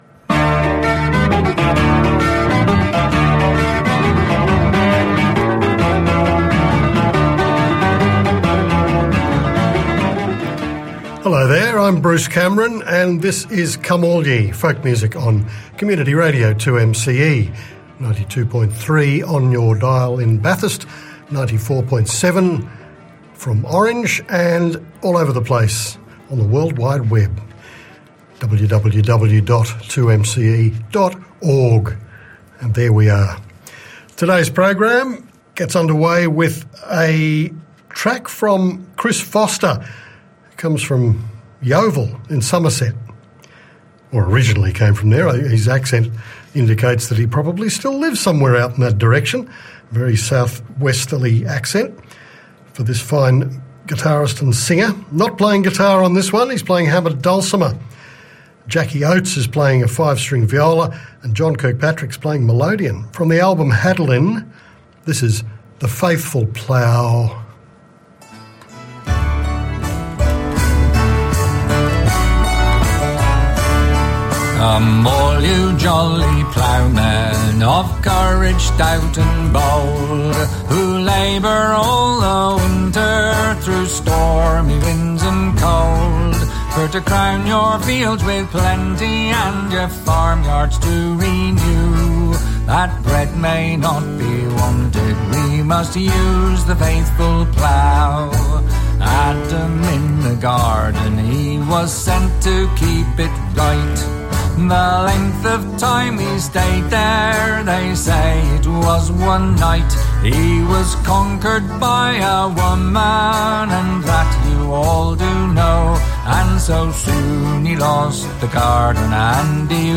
A solid collection of traditional and original Scots ballads, and tune sets with smallpipes, whistle and guitar.